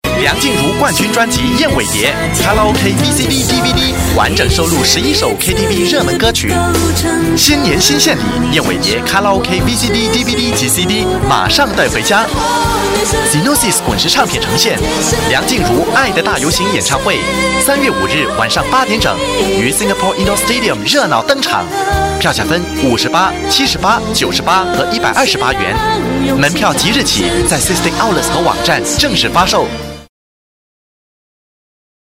Voice Samples: Mandarin Voice Sample 04
male